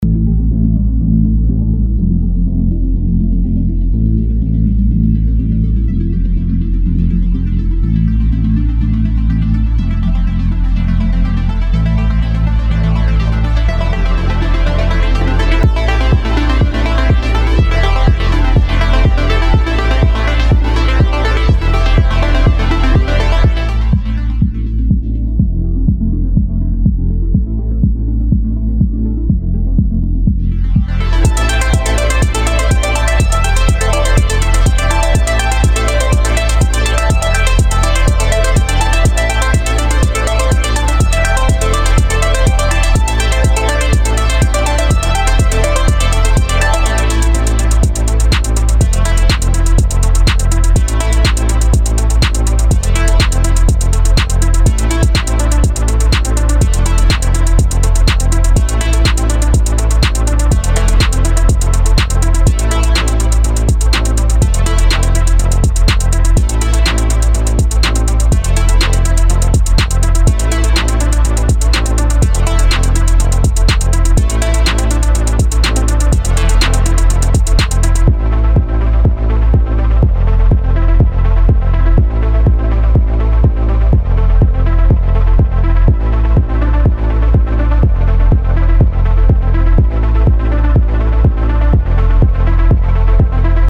loops well, lmk if you need the .flp i cant attach it for some reason
cyberpunk.mp3